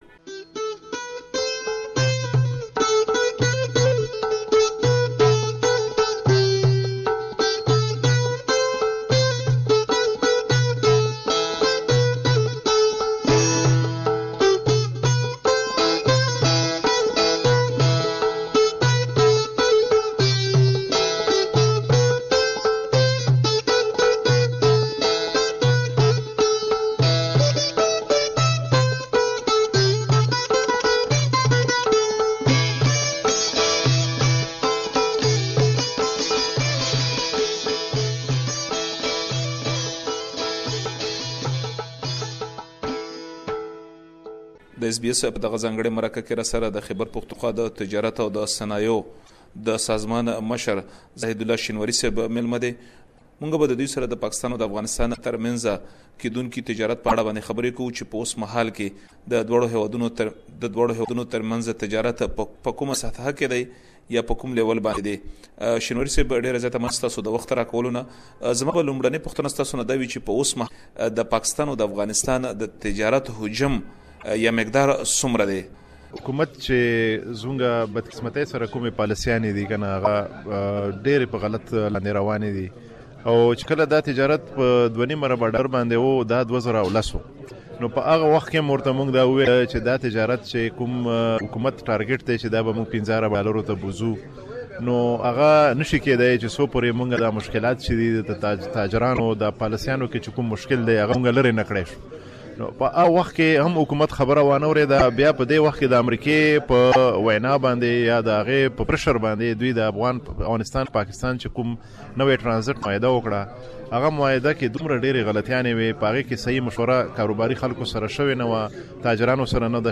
مرکه ترسره کړې